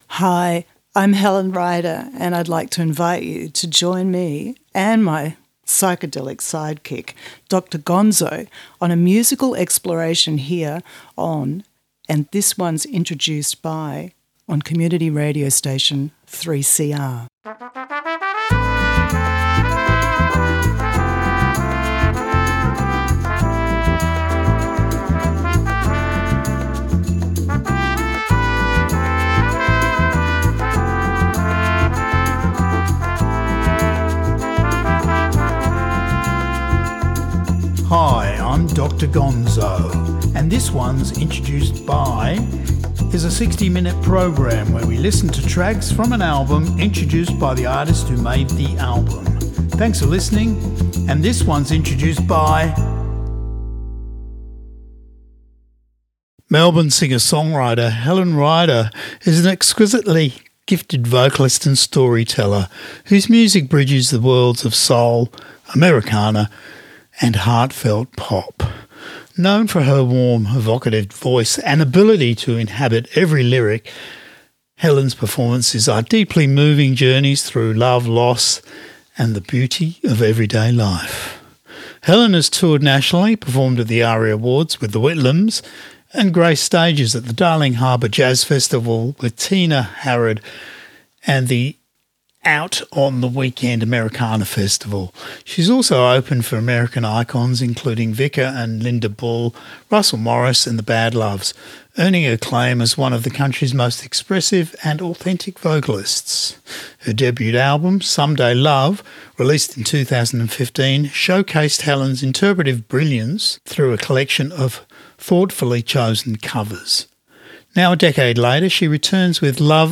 Melbourne singer-songwriter